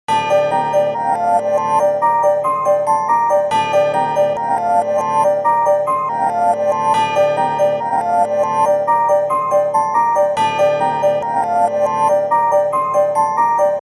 • Качество: 192, Stereo
громкие
без слов
Electronica
Жутко приятная мелодия